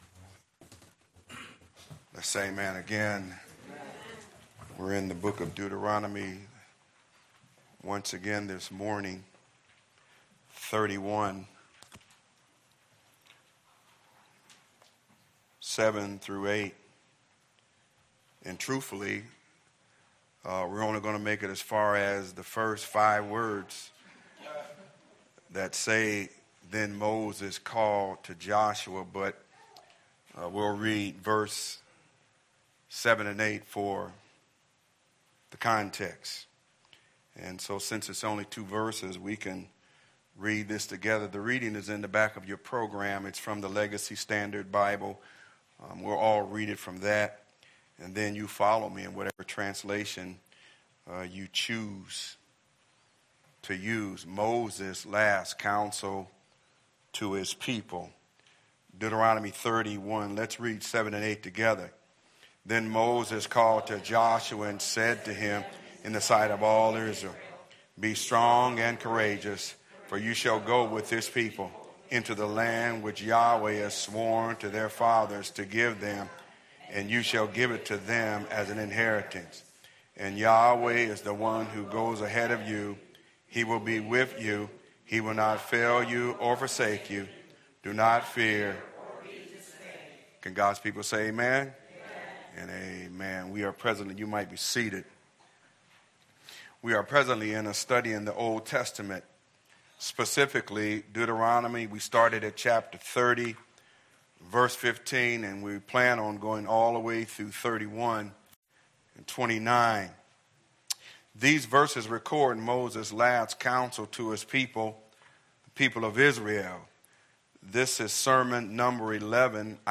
Sermons | The Word of God Community Church